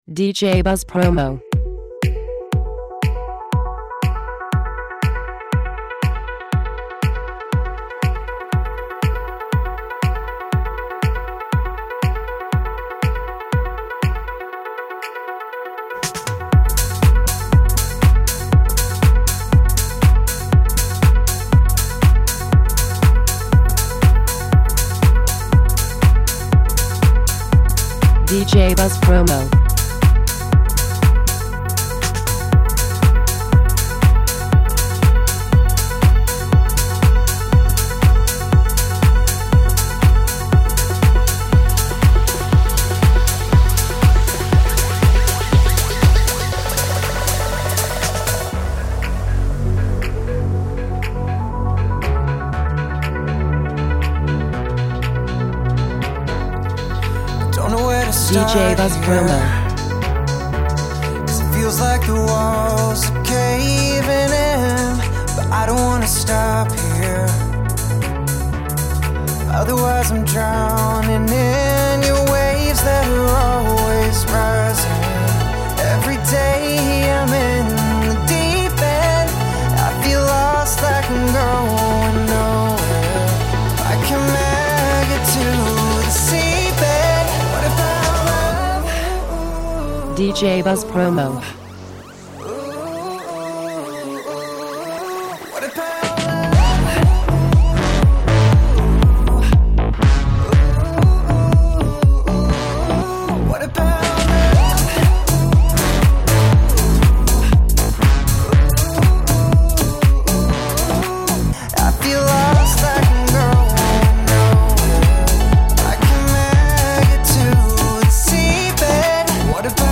French Electro-House